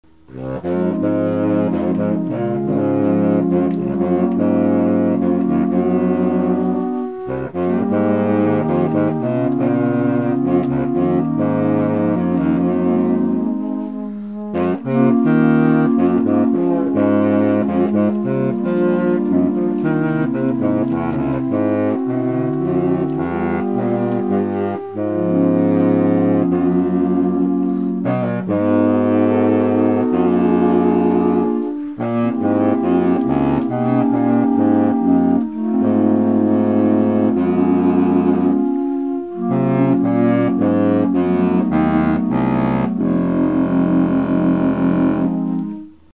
(5/22/01) Here's a lunchtime goof: "